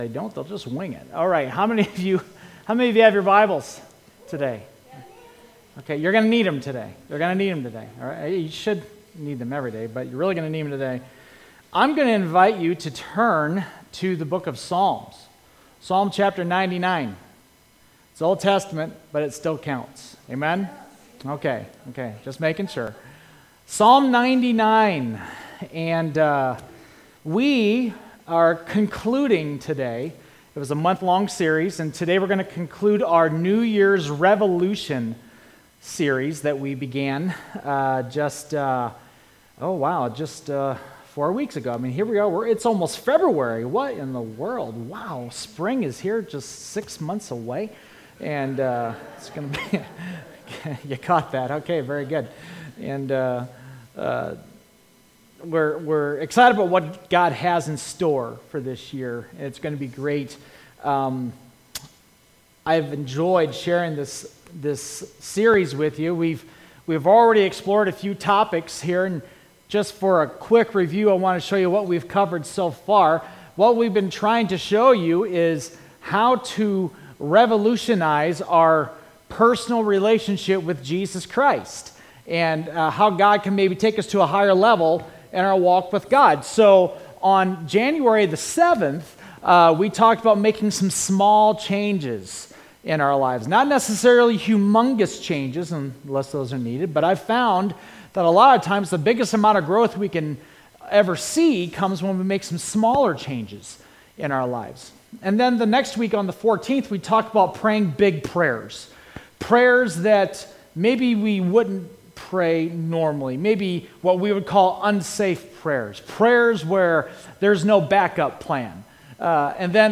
Sermons - Bethel Church of Tallmadge